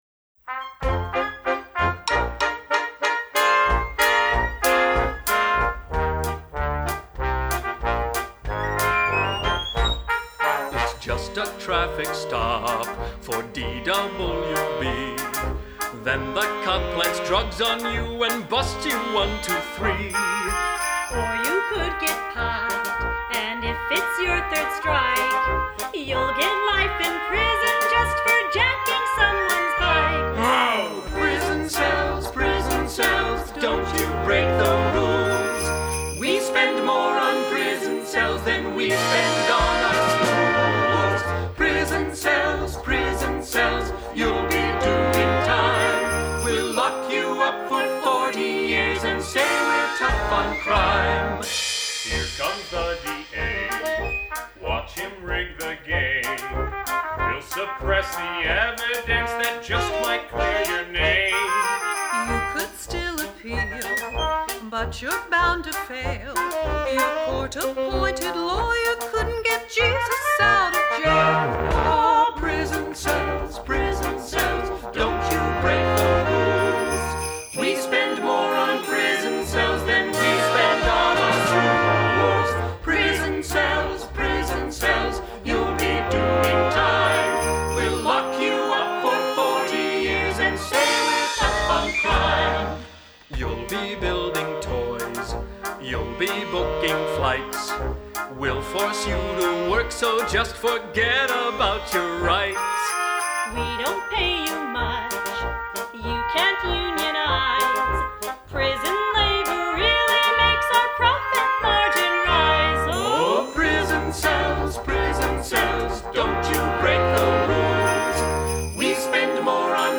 Christmas Music